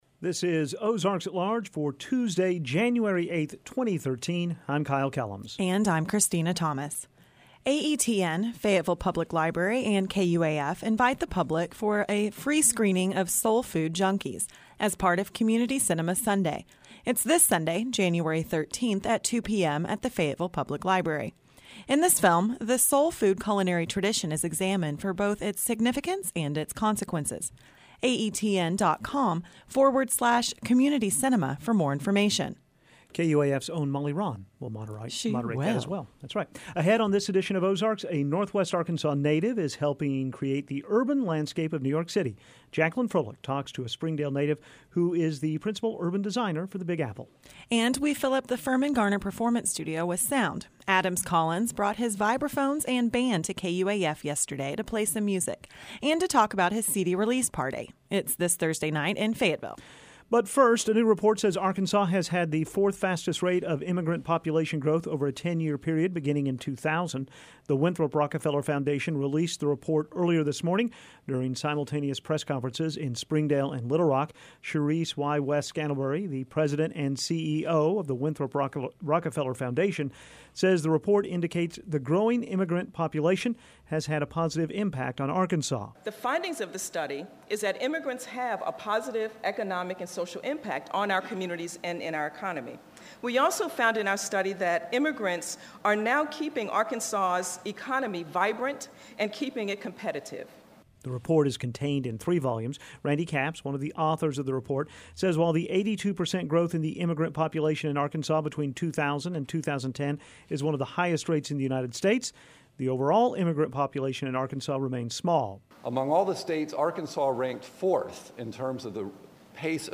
And we fill up the Firmin Garner Performance Studio with sound.